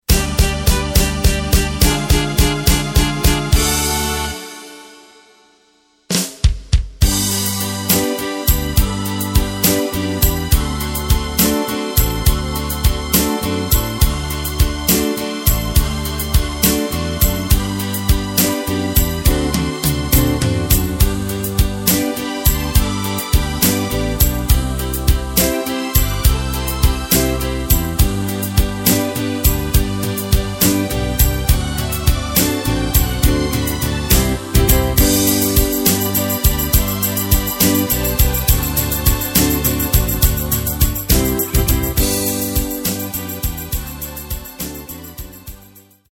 Takt:          4/4
Tempo:         105.00
Tonart:            G
Schlager aus dem Jahr 1975!
Playback mp3 Demo